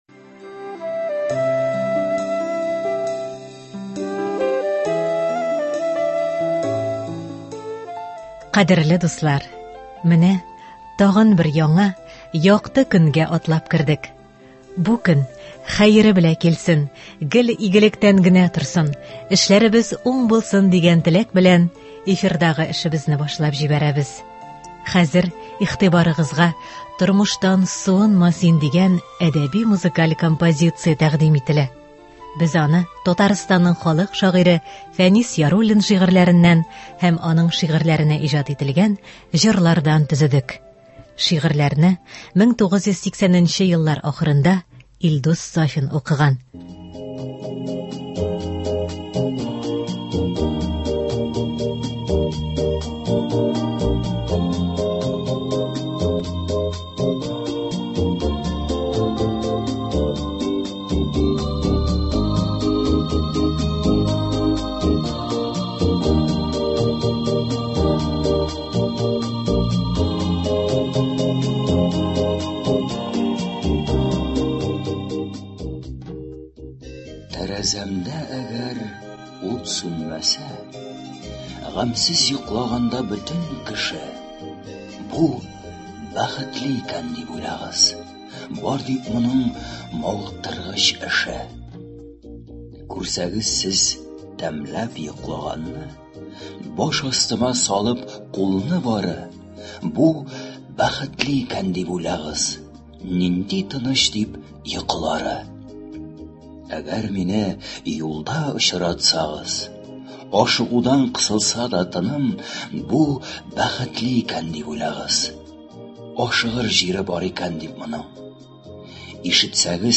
“Тормыштан суынма син”. Фәнис Яруллин әсәрләреннән әдәби-музыкаль композиция.